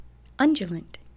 The adjective is "undulant," pronounced "UN djuh lunt."
undulant.mp3